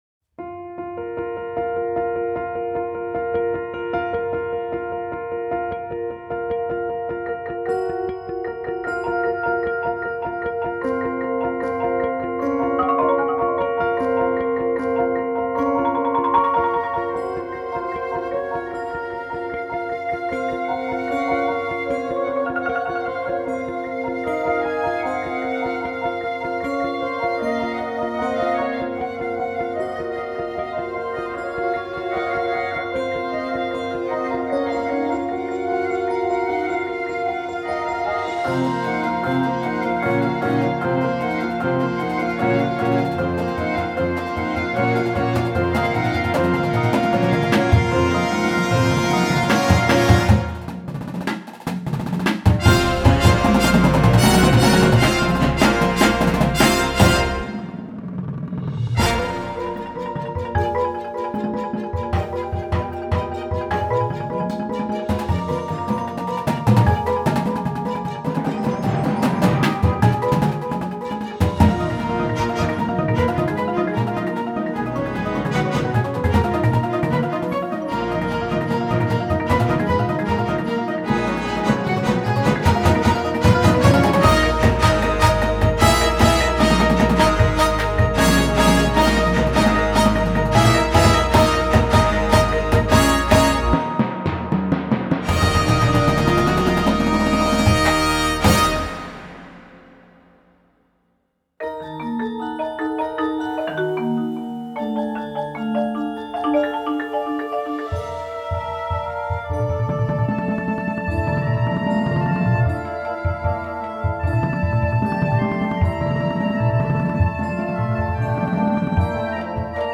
Marching Band Shows
Winds
Percussion